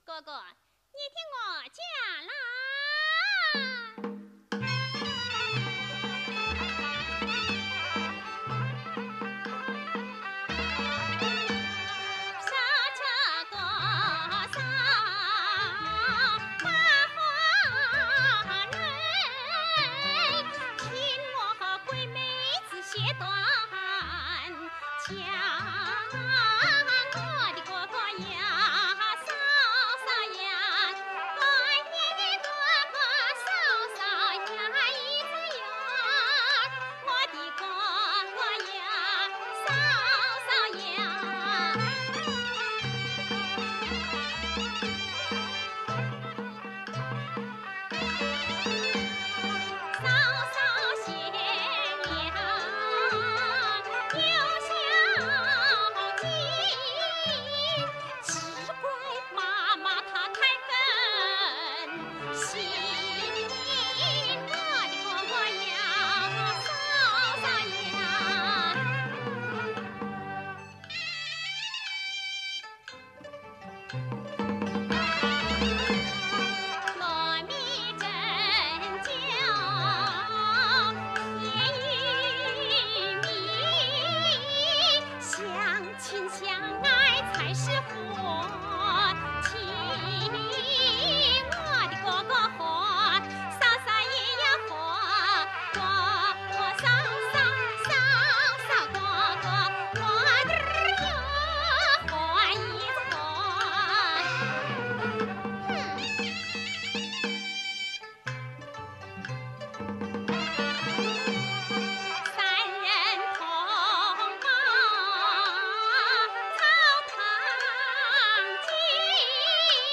和调